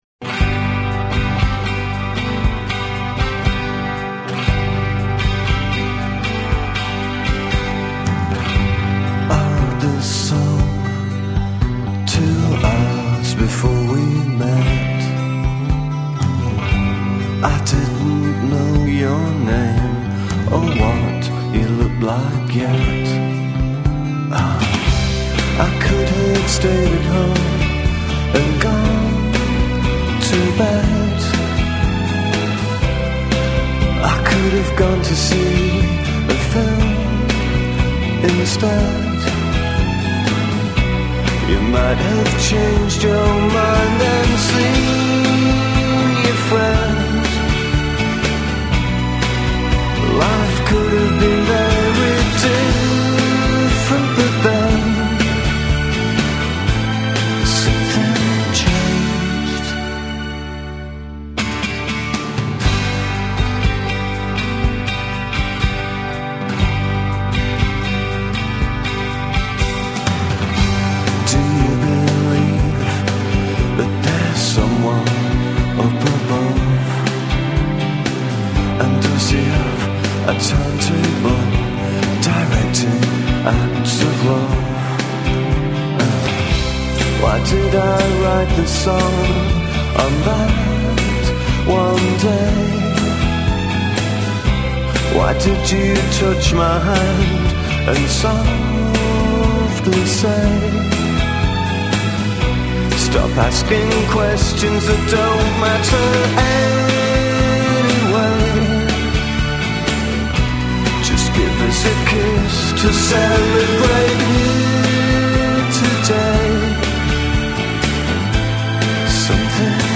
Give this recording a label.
Genre: Britpop